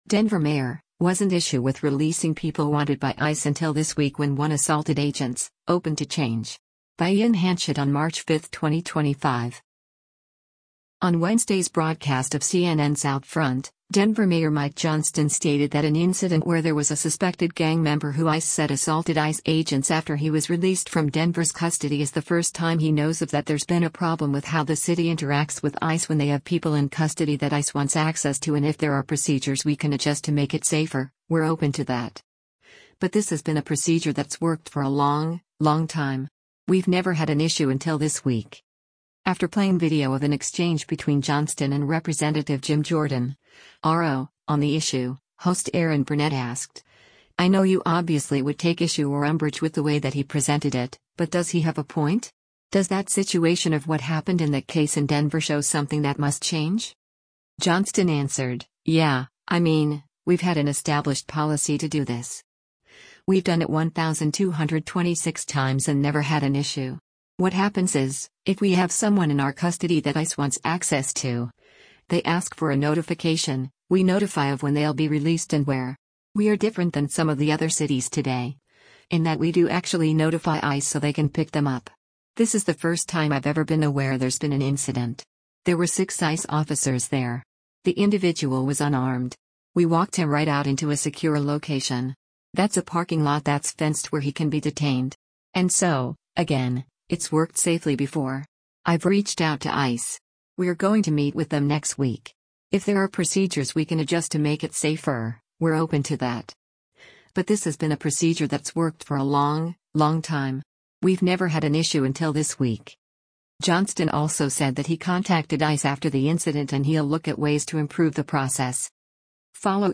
After playing video of an exchange between Johnston and Rep. Jim Jordan (R-OH) on the issue, host Erin Burnett asked, “I know you obviously would take issue or umbrage with the way that he presented it, but does he have a point? Does that situation of what happened in that case in Denver show something that must change?”